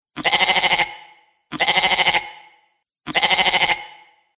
Звуки козы, козла
Козел бекает